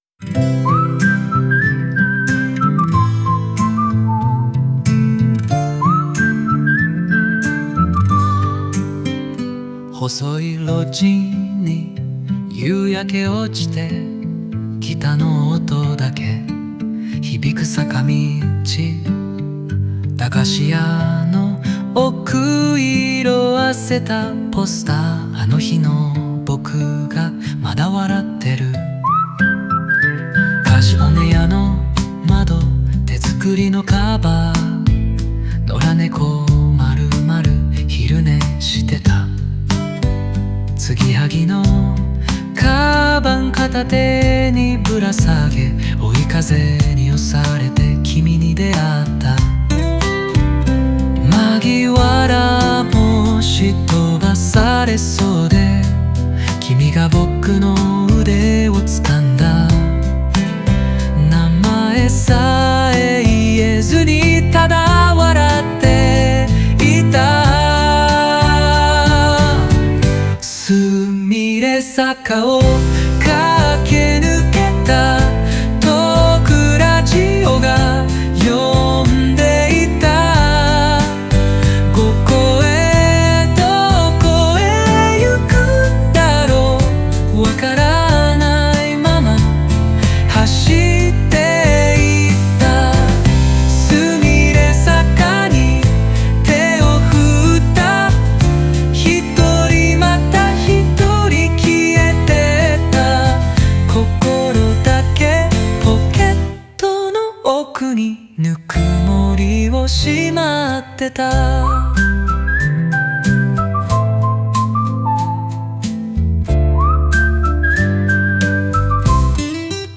オリジナル曲♪
Music(音楽) Music(音楽) (1630)